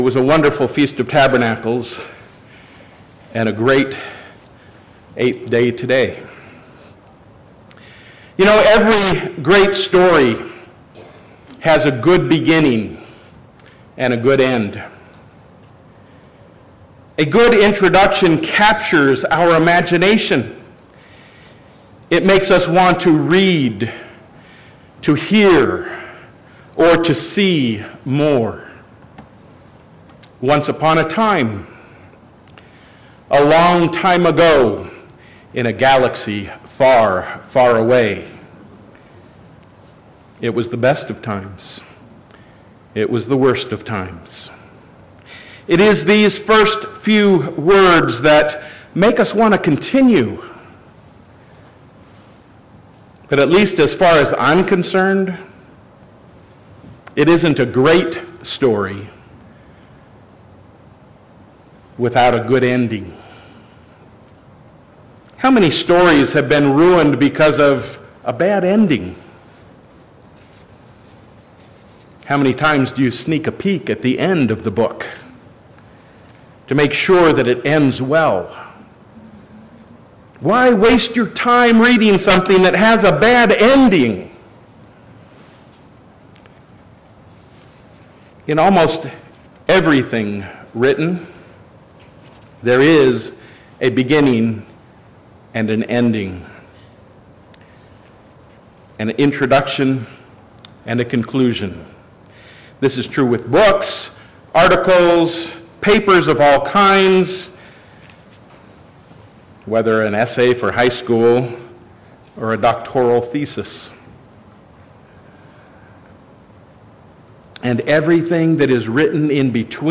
This sermon was given at the Lake George, New York 2018 Feast site.